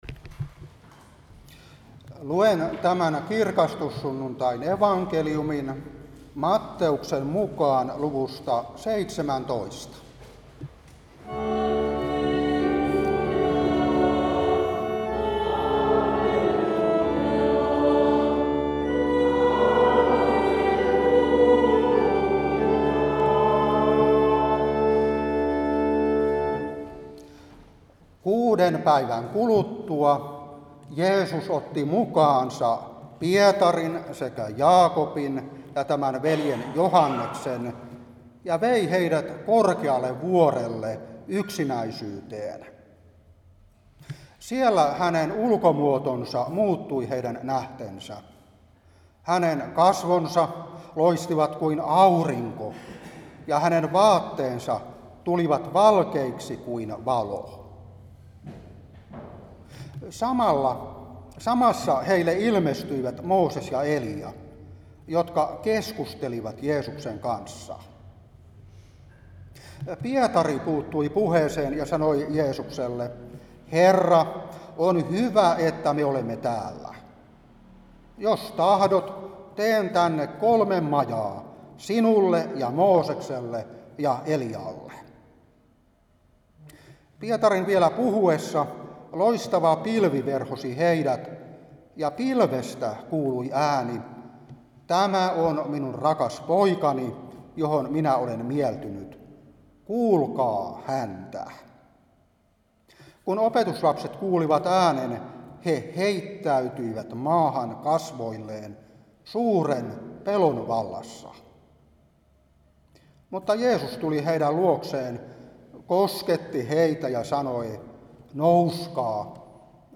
Saarna 2025-8.